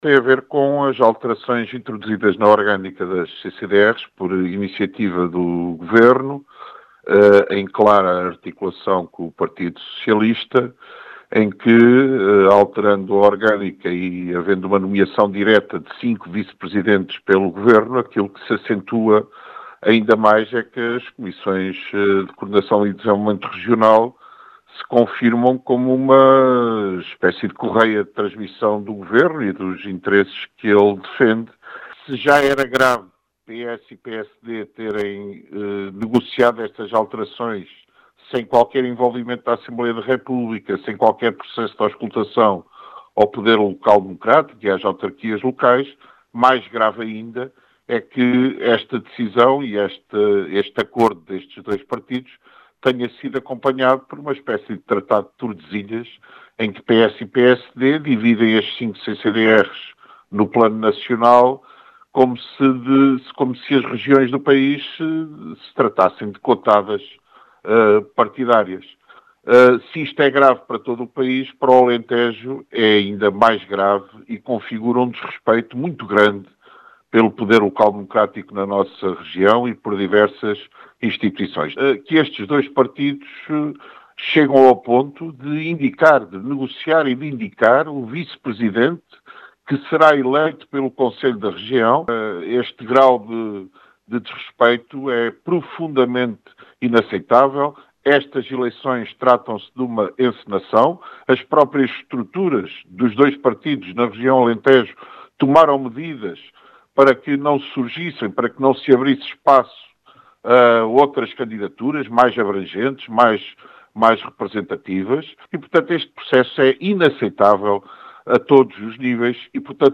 As explicações foram deixadas à Rádio Vidigueira